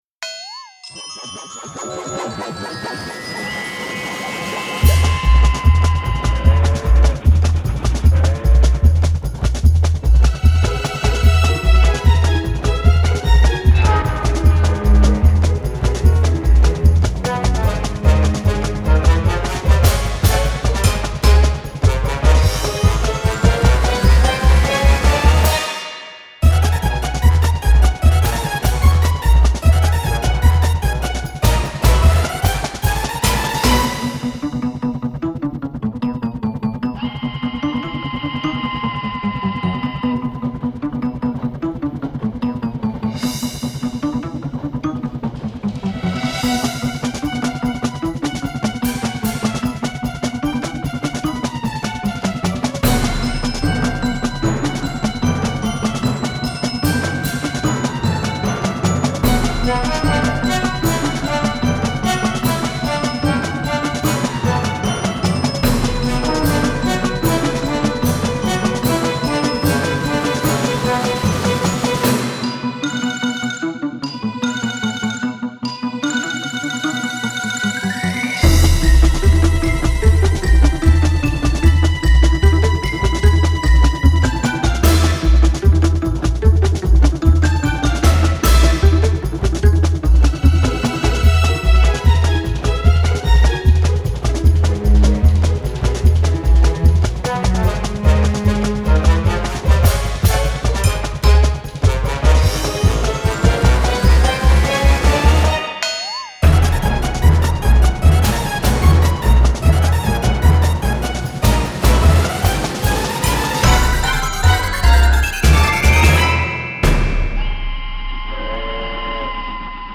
video game music examples